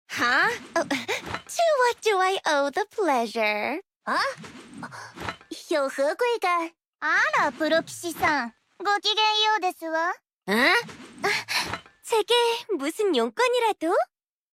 (ENG vs CN vs JP vs KRN) Luciana de Montefio Voice Actor Comparison